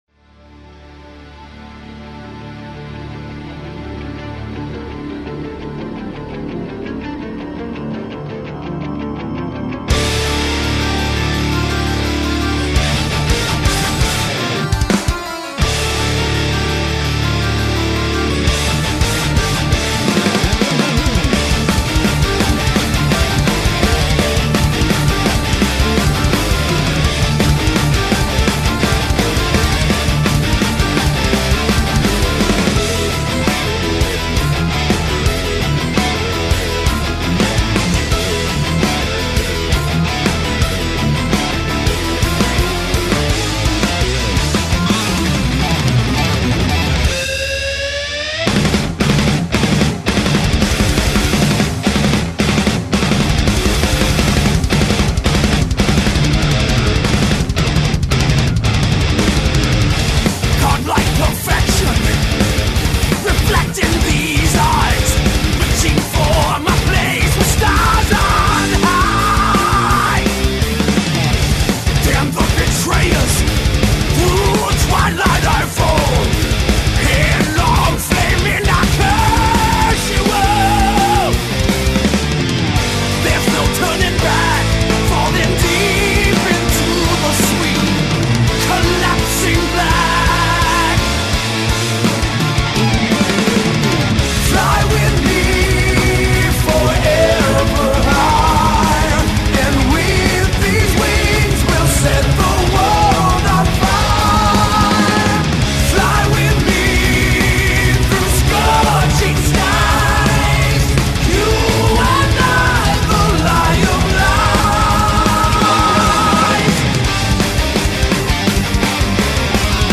un esempio di furioso heavy metal barocco